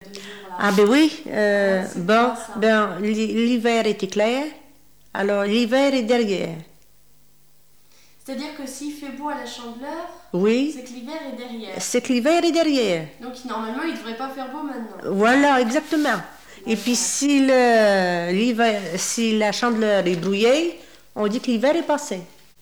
Genre dicton
Catégorie Récit